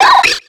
Cri de Riolu dans Pokémon X et Y.